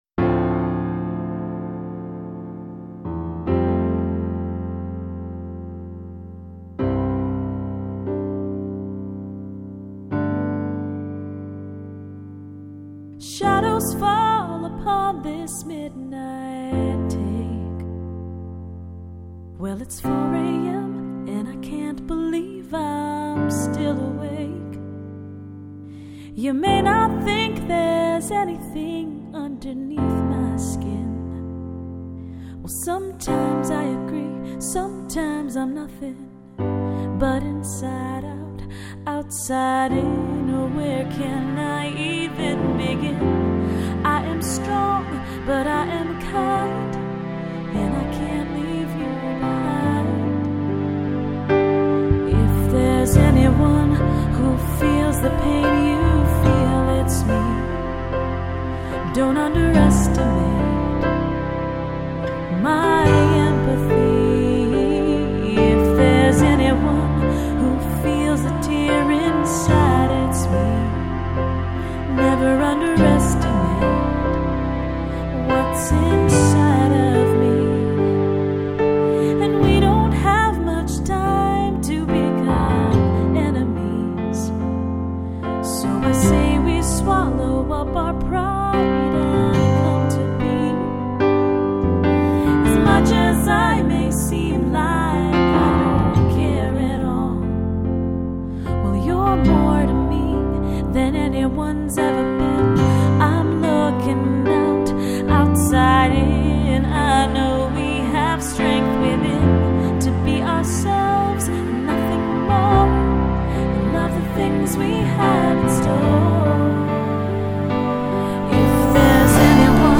(pop/AC)